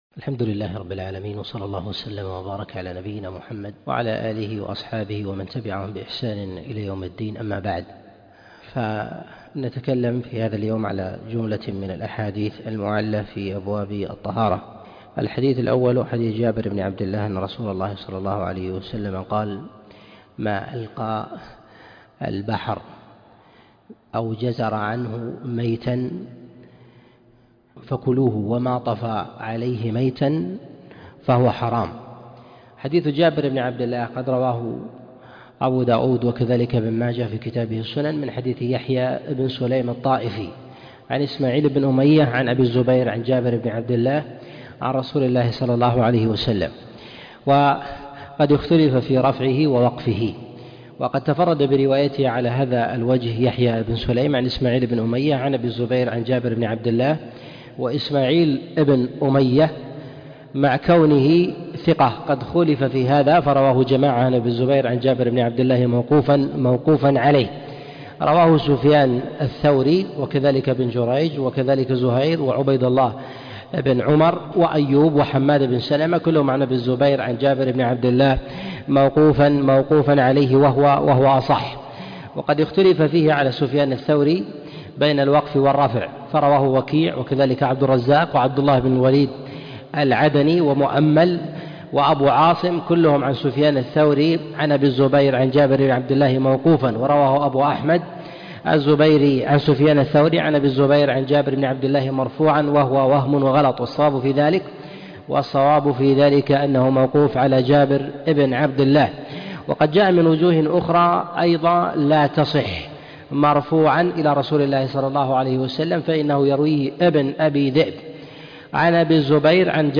الأحاديث المعلة في الطهارة الدرس 5 - الشيخ عبد العزيز بن مرزوق الطريفي